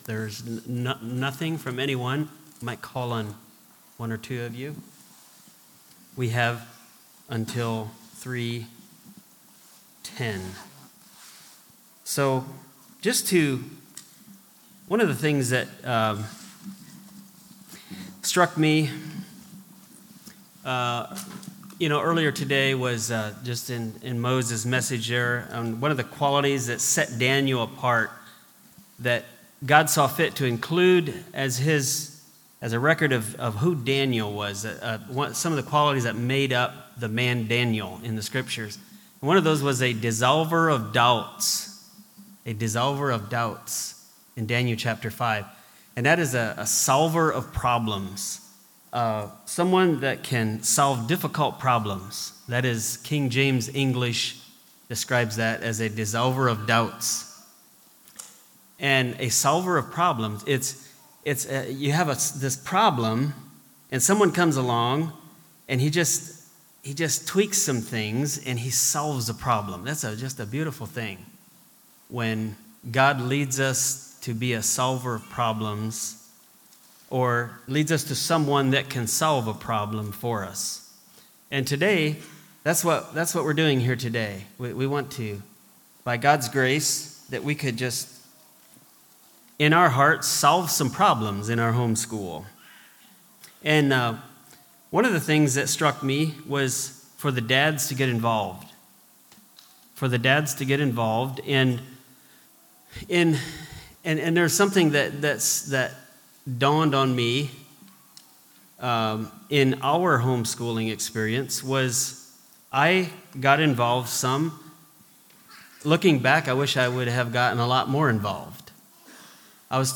Panel Discussion
11-Panel-Discussion.mp3